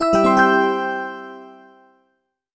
ec_alert5.mp3'